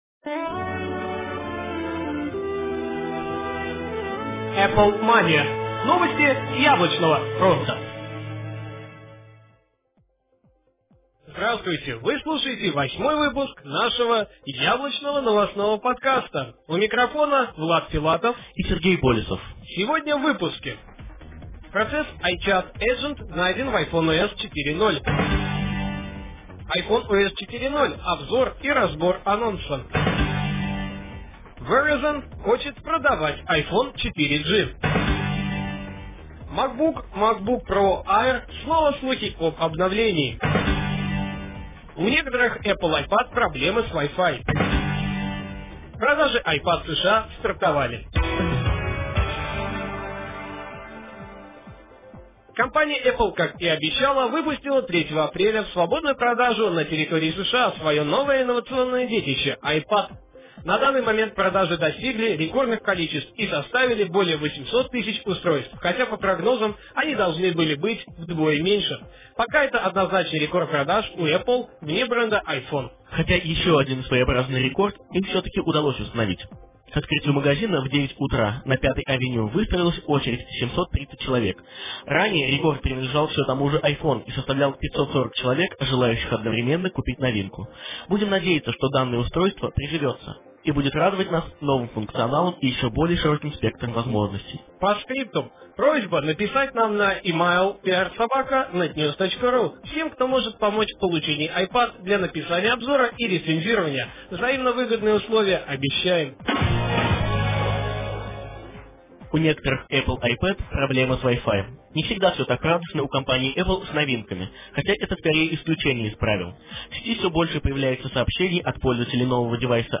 Жанр: новостной Apple-podcast